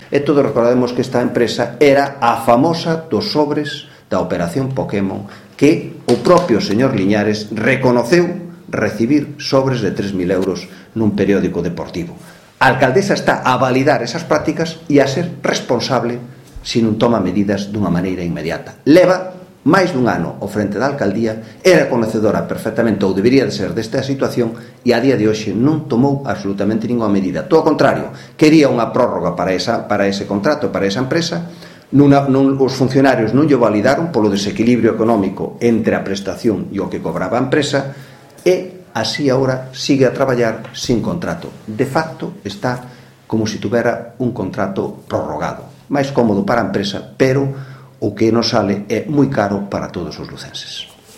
O voceiro do Grupo Municipal do Partido Popular Jaime Castiñeira denunciou esta mañá en rolda de prensa pagos por 215.382 euros “sen contrato nin prórroga legal de ningún tipo” á empresa Cechalva, un dos eixes do Caso Pokémon, “malia á oposición do interventor municipal, quen cuestionou a legalidade dos pagos”.